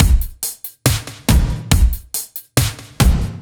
Index of /musicradar/french-house-chillout-samples/140bpm/Beats
FHC_BeatB_140-01.wav